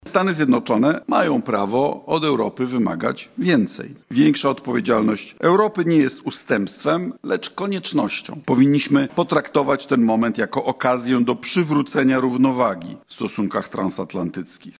Wicepremier, minister spraw zagranicznych Radosław Sikorski podczas debaty Regionalnego Ośrodka Debaty Międzynarodowej w Lublinie nakreślił nowe realia bezpieczeństwa Europy i rolę relacji transatlantyckich.
– mówił wicepremier i minister spraw zagranicznych Radosław Sikorski